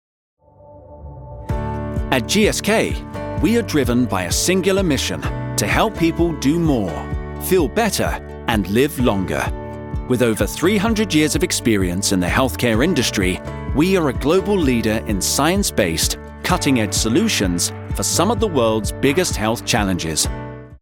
Inglés (Británico)
Comercial, Natural, Versátil, Cálida, Empresarial
Explicador